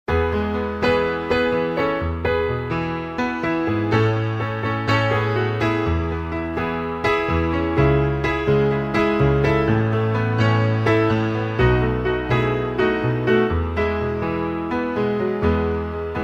Instrumental mp3 Track